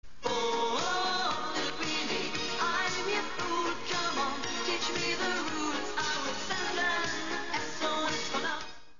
Песня на аудио, лица справа.